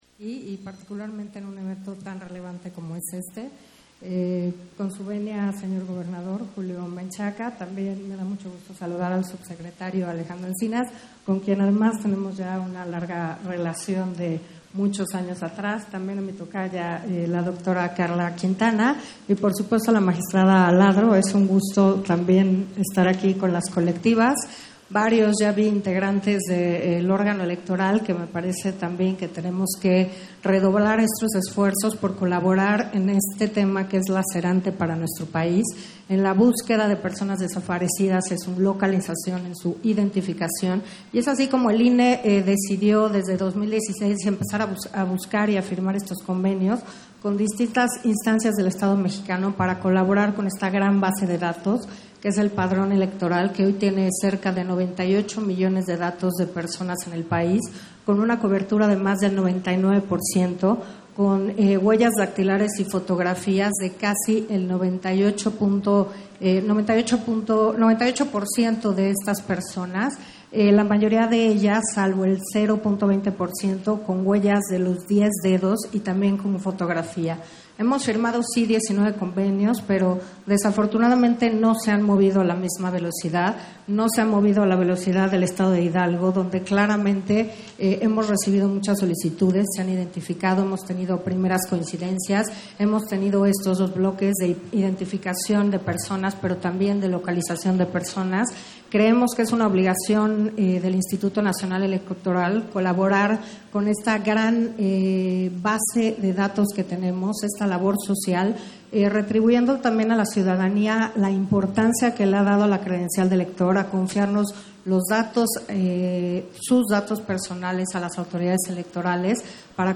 030723_AUDIO_INTERVENCIÓN-CONSEJERA-HUMPHREY-AVANCES-DE-LA-ESTRATEGIA-DE-BÚSQUEDA - Central Electoral